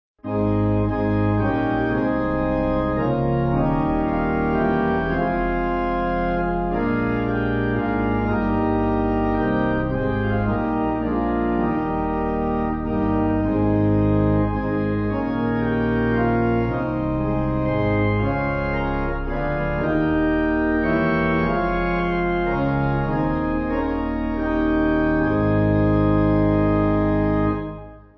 (CM)   5/Ab